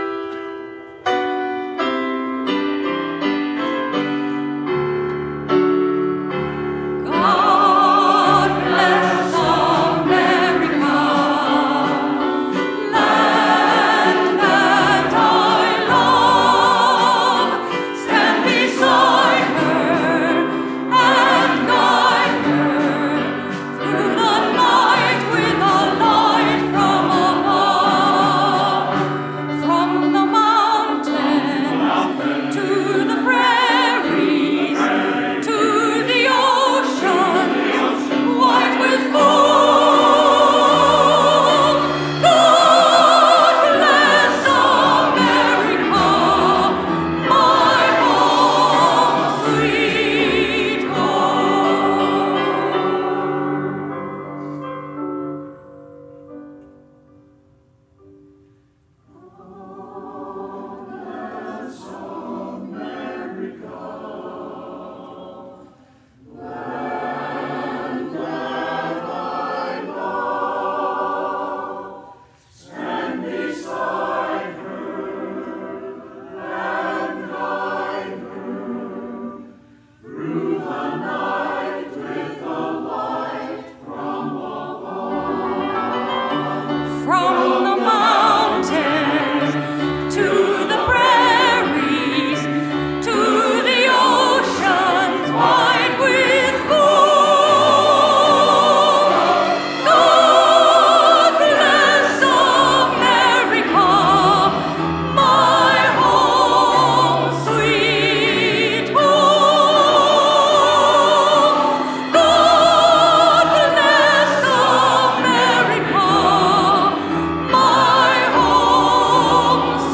**Back in 2015, I sang “God Bless America’ with the 70 voice Rappahannock Choral Society (RCS).